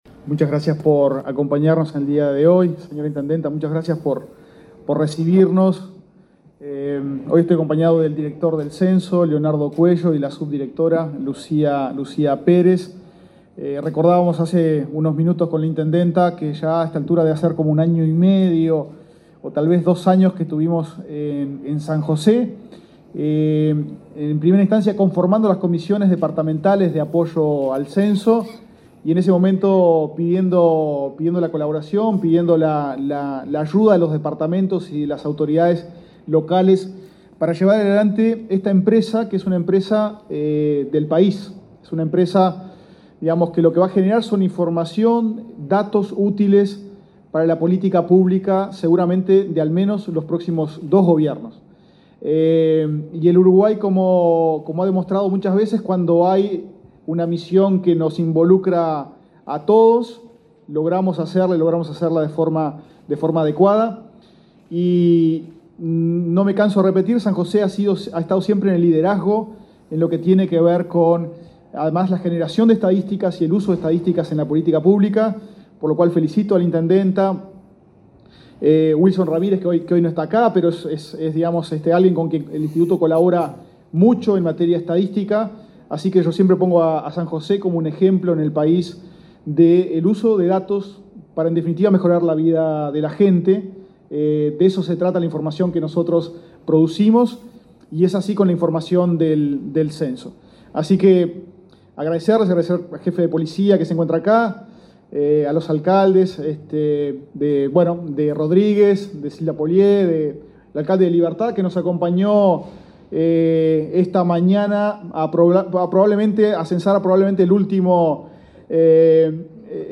Declaraciones de autoridades del Instituto Nacional de Estadística
Declaraciones de autoridades del Instituto Nacional de Estadística 18/09/2023 Compartir Facebook X Copiar enlace WhatsApp LinkedIn El director del Instituto Nacional de Estadística (INE), Diego Aboal, y el director del Censo, Leonardo Cuello, brindaron una conferencia de prensa en San José para informar sobre la finalización del relevamiento presencial, en ese departamento.